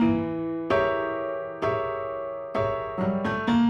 Index of /musicradar/gangster-sting-samples/130bpm Loops
GS_Piano_130-E2.wav